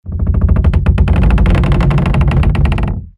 wood_creak_long